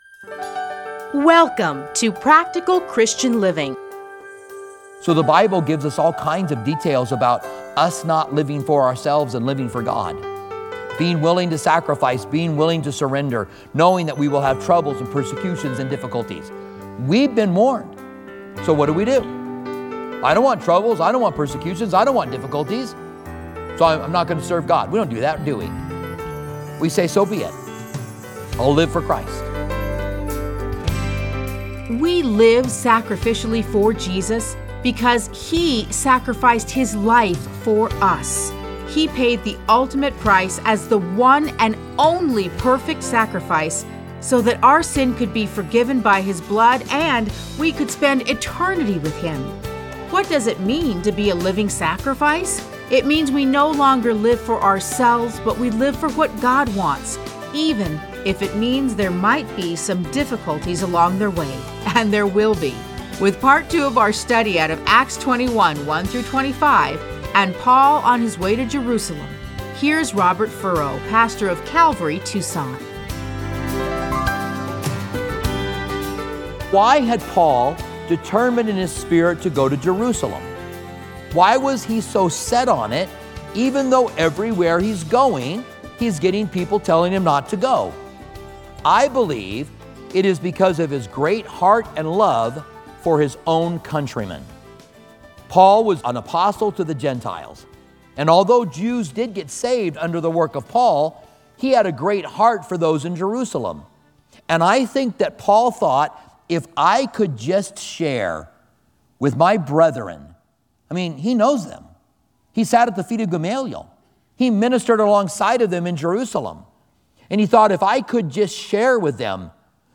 Listen to a teaching from Acts 21:1-25.